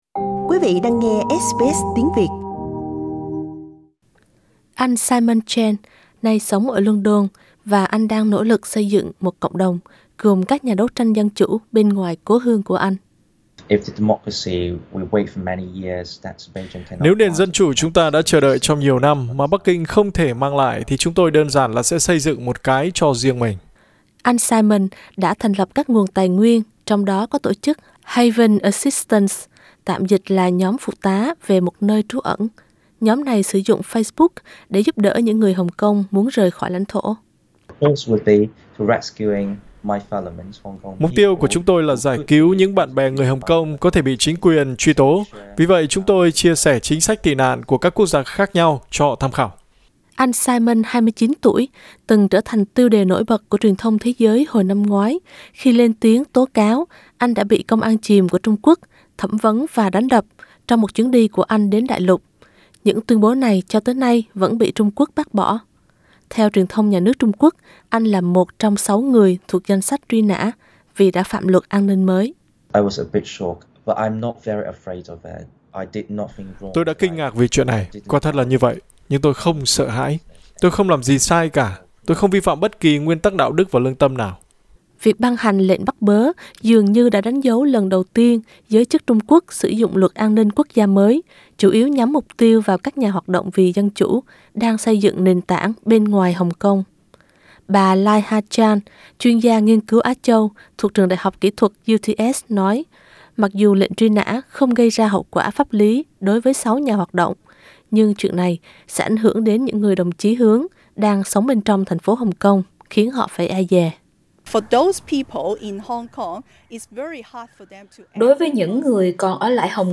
Activist Simon Cheng, speaking to SBS from London via video call Source: SBS